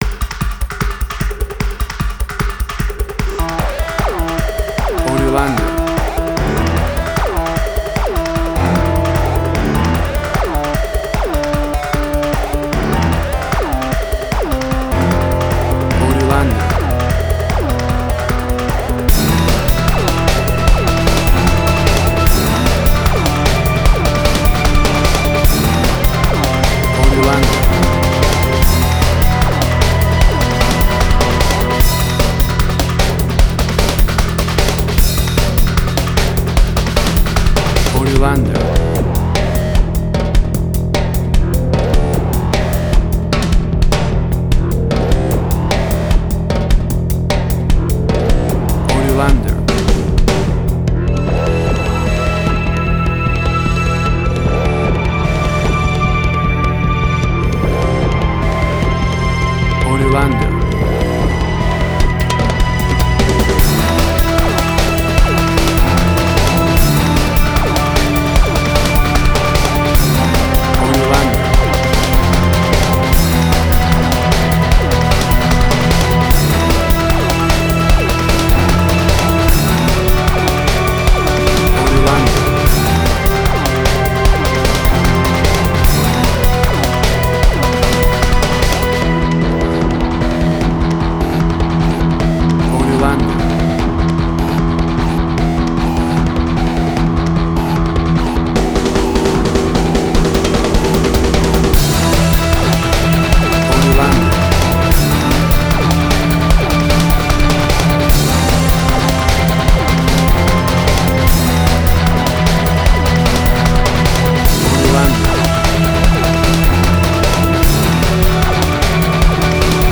Suspense, Drama, Quirky, Emotional.
Tempo (BPM): 150